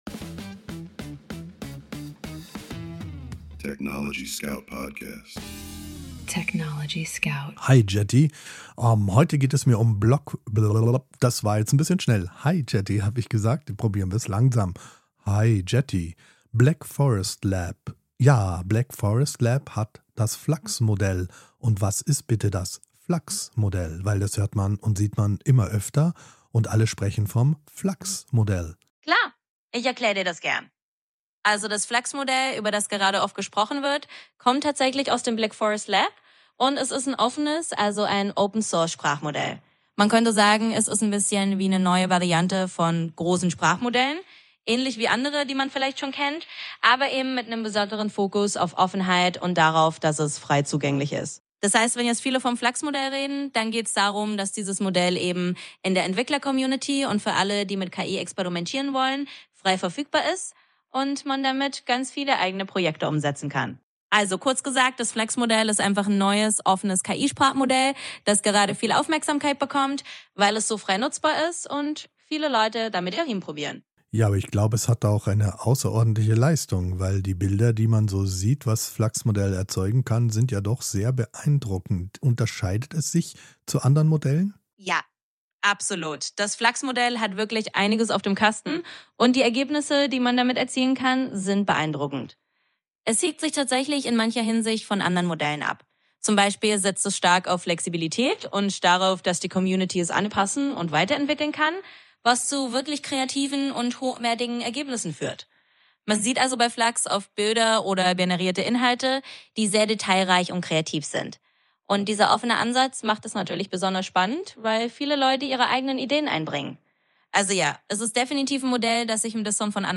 Unsere Gespräche sind voller Aha-Momente, Denkanstöße und überraschender Einsichten.